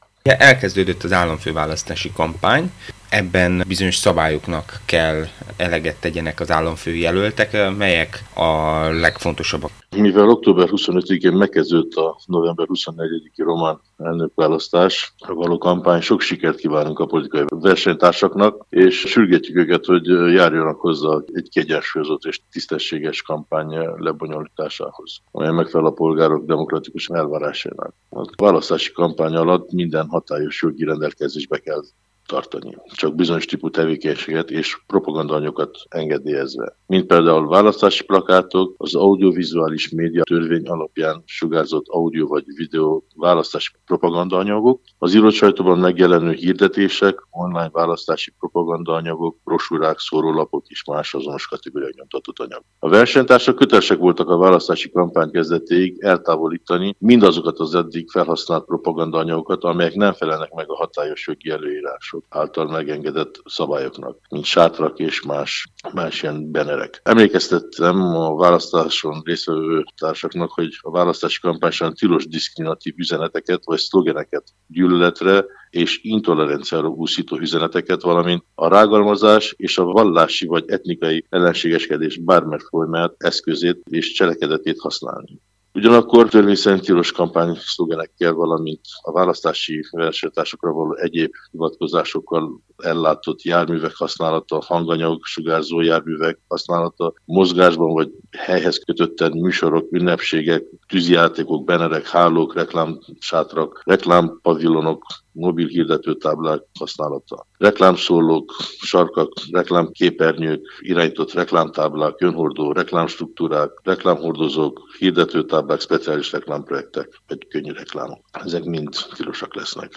Erről kérdeztük Vajda Zsombort, az Állandó Választási Hatóság alelnökét.